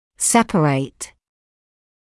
[‘sepəreɪt][‘сэпэрэйт]отделять, разделять; [‘sepərət] отдельный, обособленный; изолированный